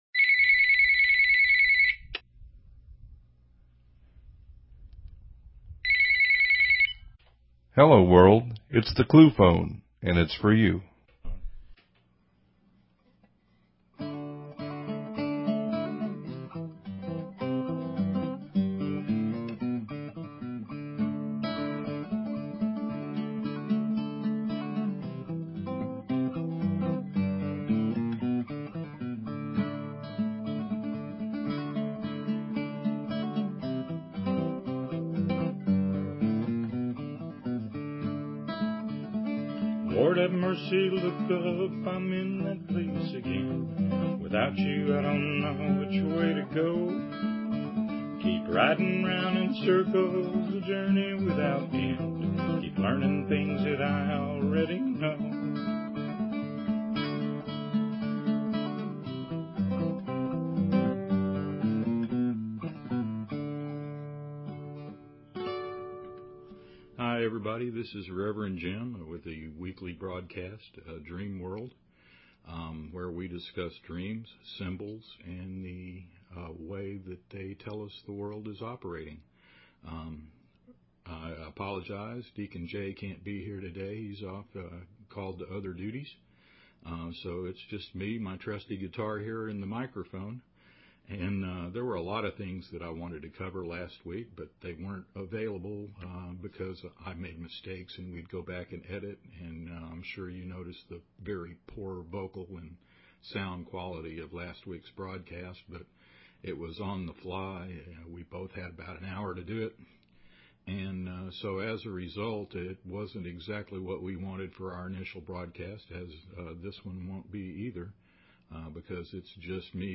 Talk Show Episode, Audio Podcast, Dream_World and Courtesy of BBS Radio on , show guests , about , categorized as